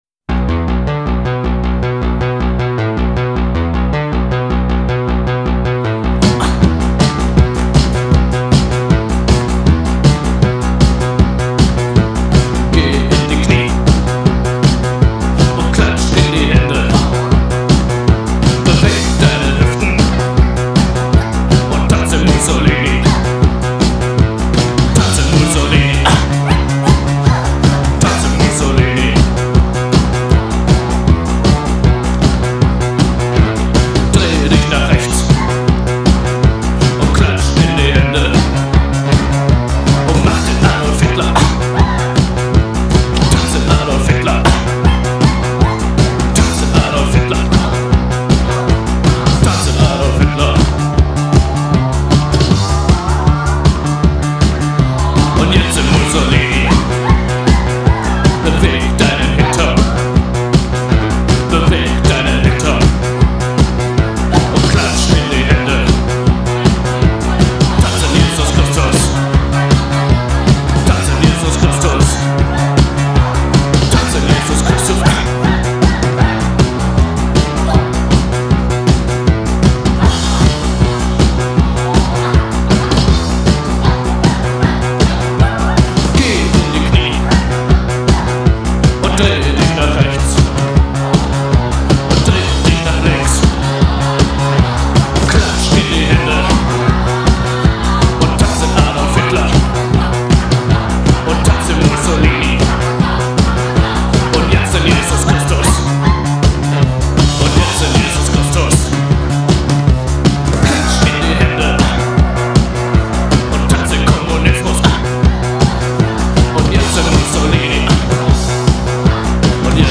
The 80's minimal sound.